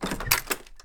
door-open-1.ogg